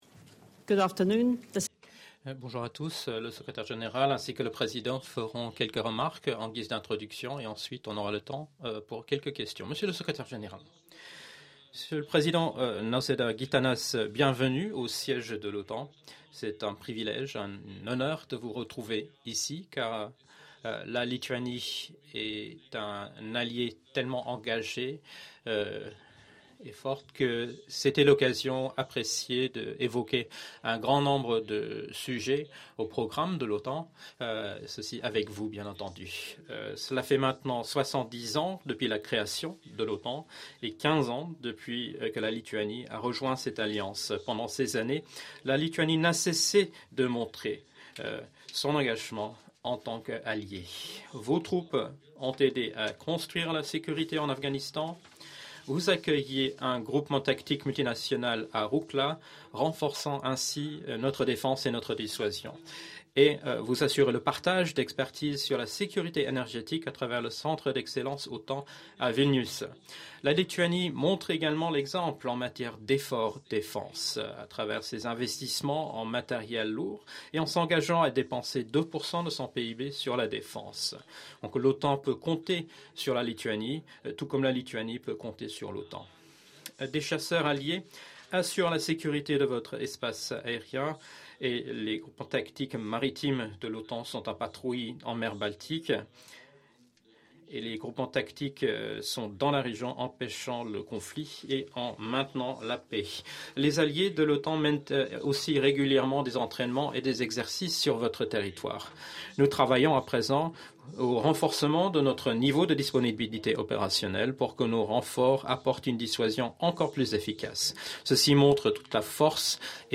Joint press point by NATO Secretary General Jens Stoltenberg and President of the Republic of Lithuania Gitanas Nauseda
(As delivered)